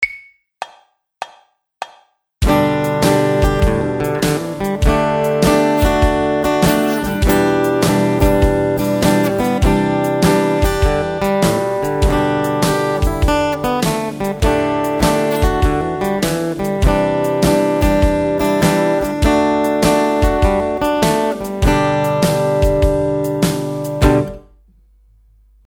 Here is a country sounding song in the key of G major that combines chord variations with the G major pentatonic scale.